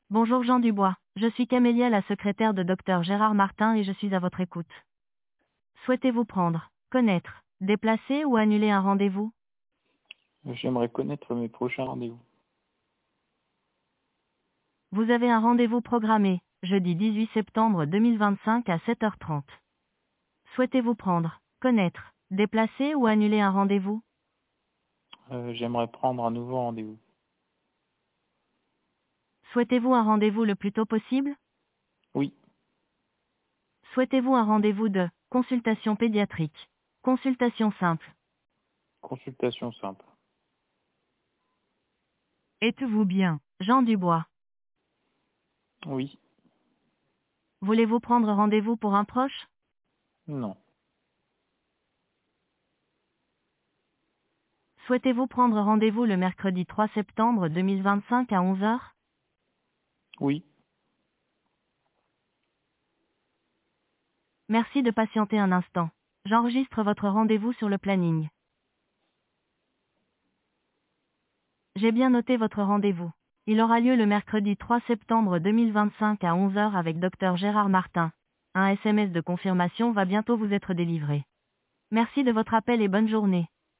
Écoutez notre secrétaire virtuelle
audio-ia-keepSR-320kbps.mp3